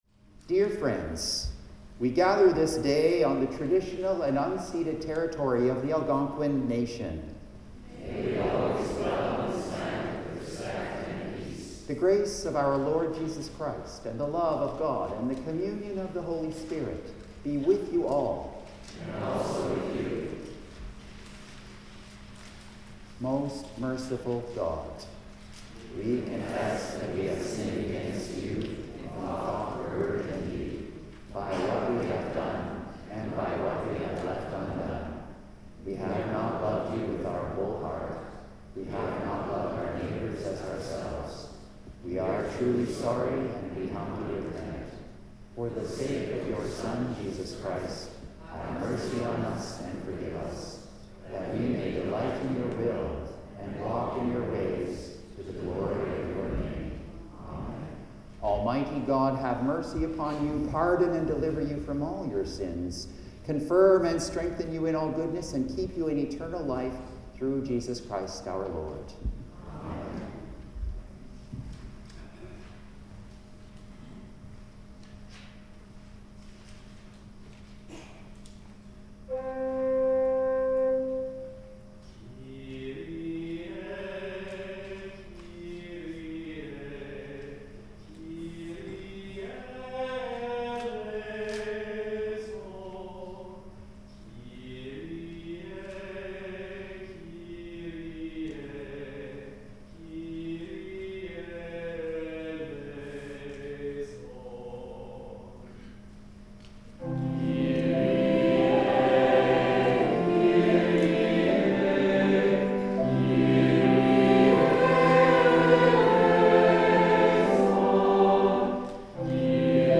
Sermon
Anthem
The Lord’s Prayer (sung)